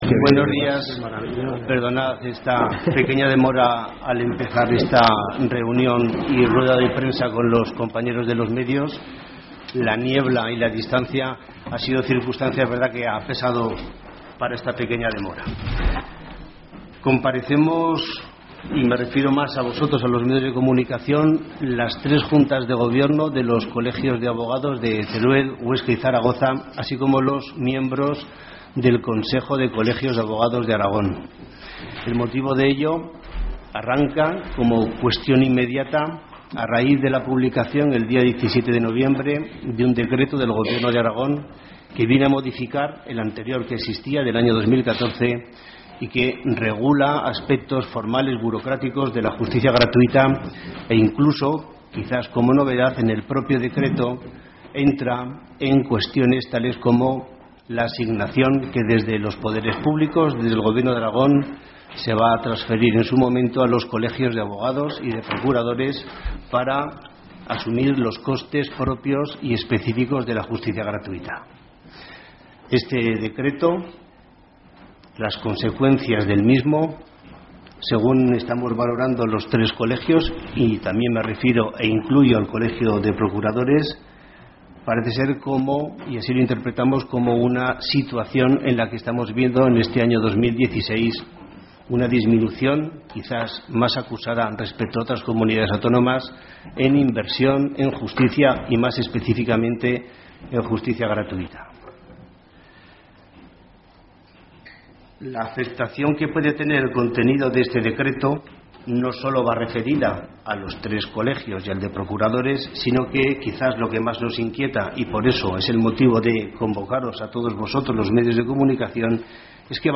( Escuchar Rueda de Prensa , ver Fotografía ) .